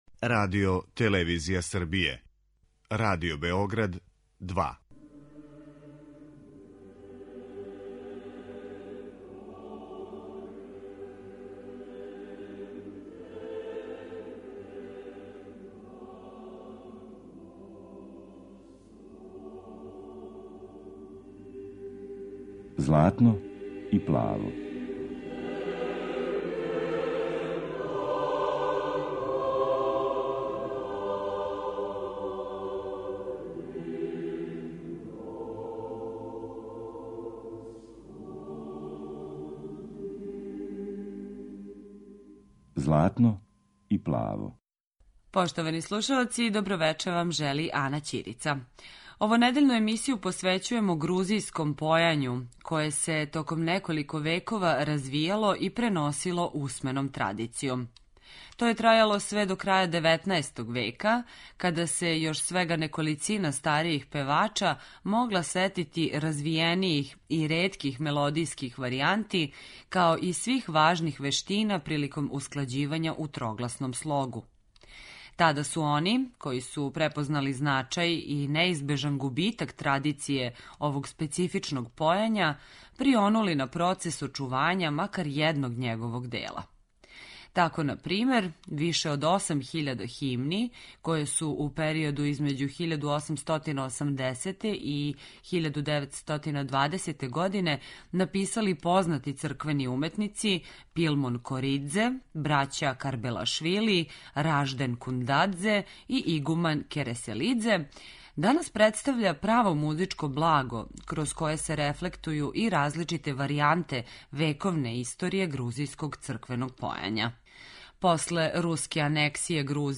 Грузијске црквене песме
Један од таквих, али модерних примера, представља ансамбл „Басиани", у чијем ћемо извођењу емитовати неколико традиционалних црквених песама.